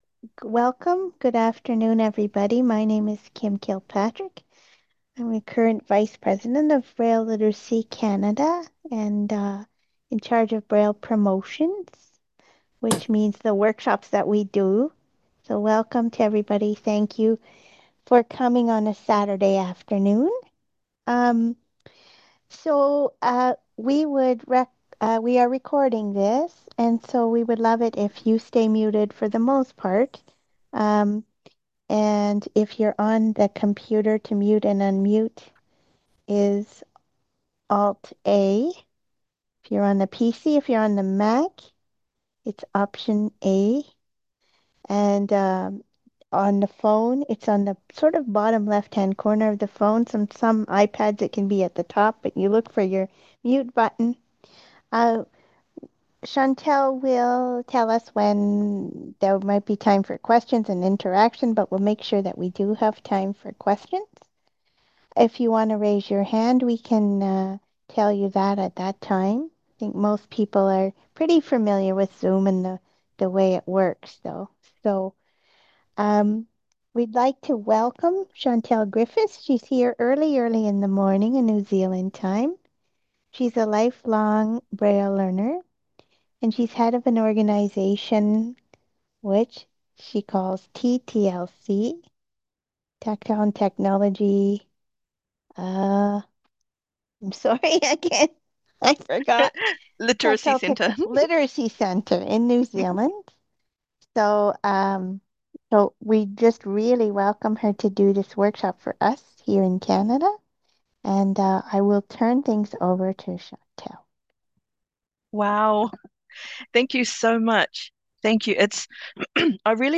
This workshop will run for 90 minutes, with an opportunity for questions.